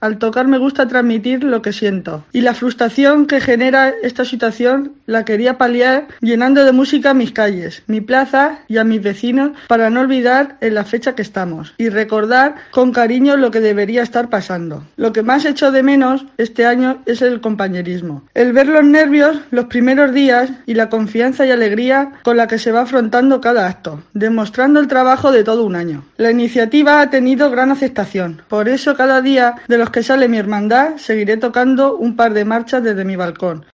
trompetista Calzada de Calatrava